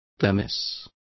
Complete with pronunciation of the translation of dermis.